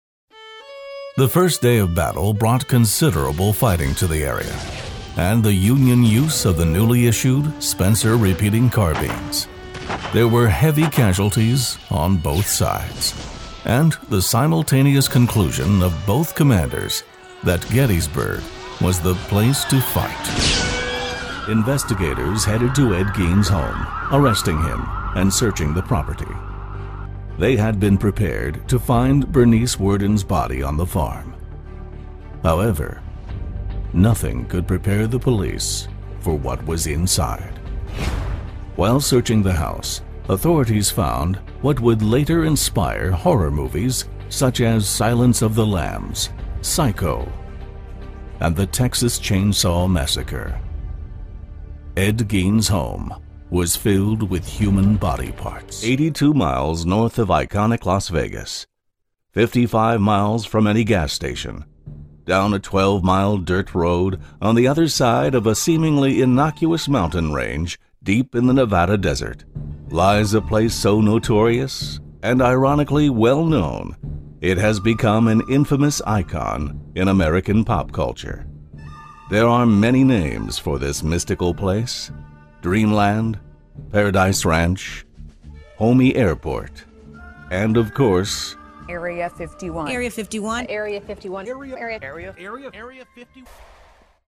Englisch (US)
Männlich
Dokumentarfilme